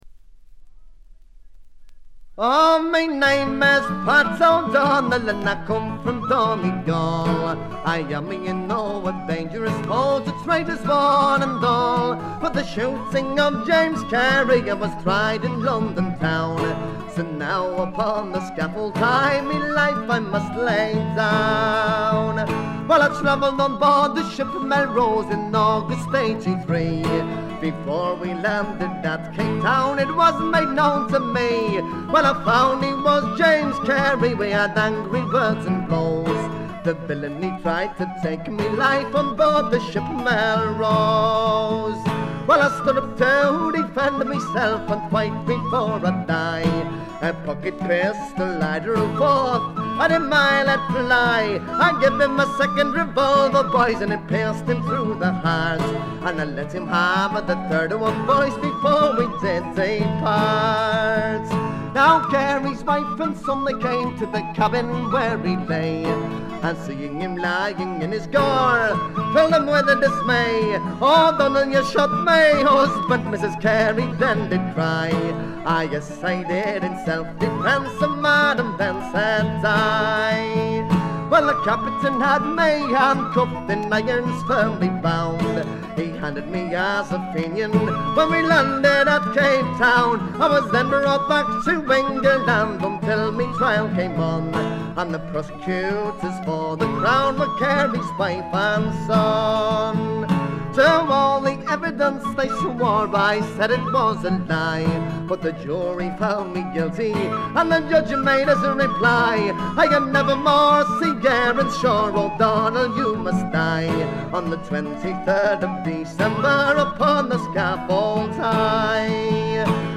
試聴曲は現品からの取り込み音源です。
Vocals ?
Guitar ?